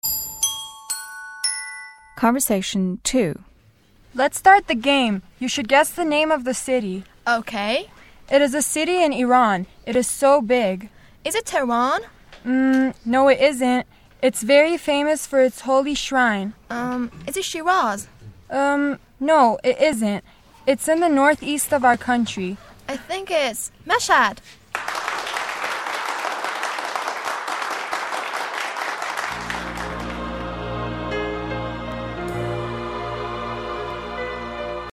مکالمه ی دوم - متن لیسنینگ listening هشتم
(صدای: کف زدن)